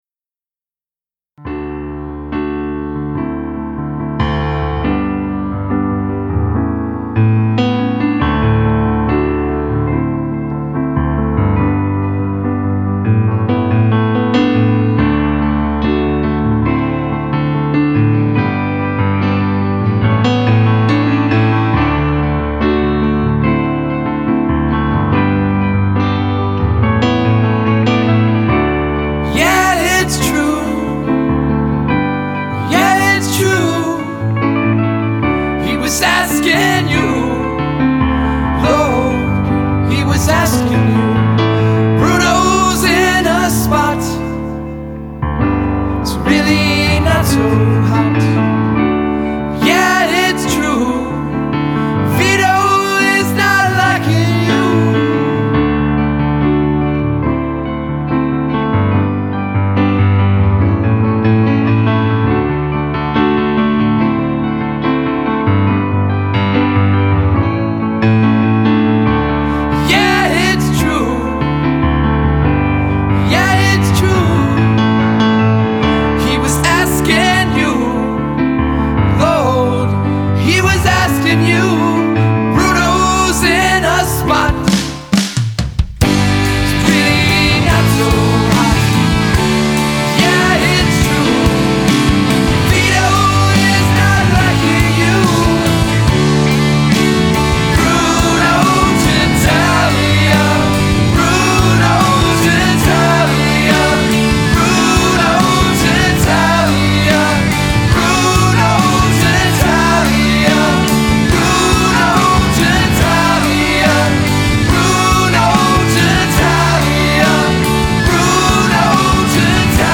Booklyn-based rock band.